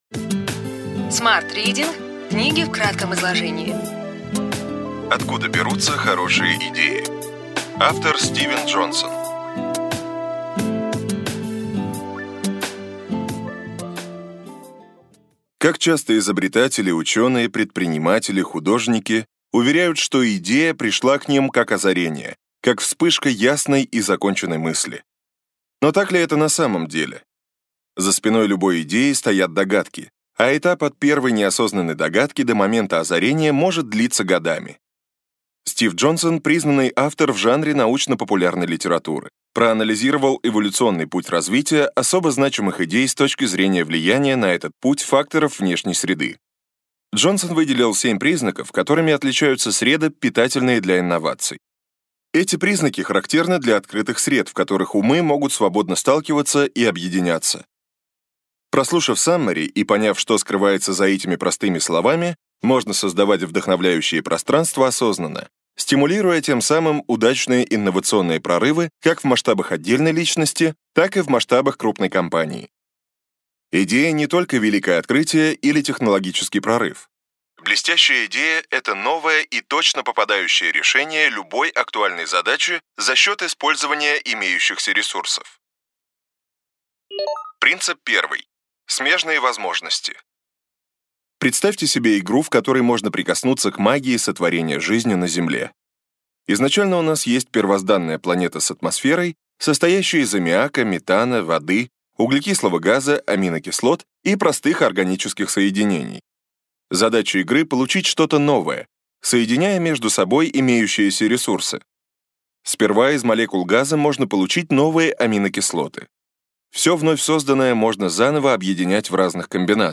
Аудиокнига Ключевые идеи книги: Откуда берутся хорошие идеи.